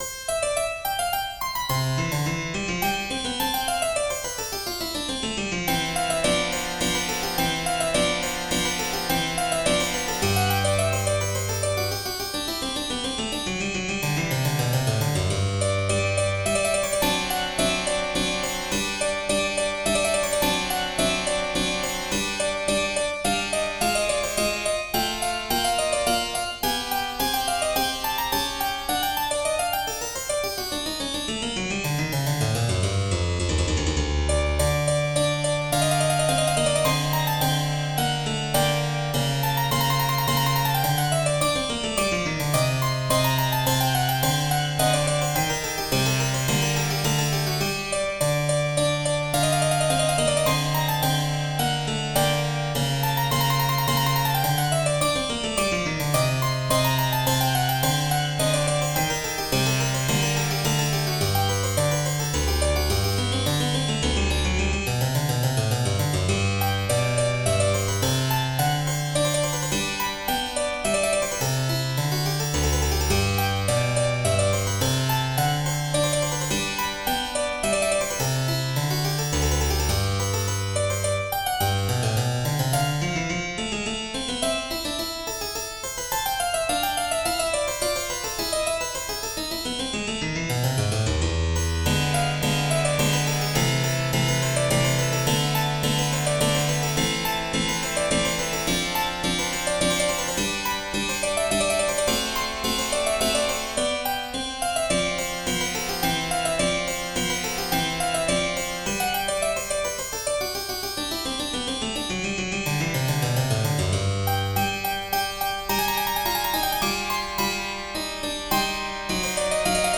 in C Major: Allegro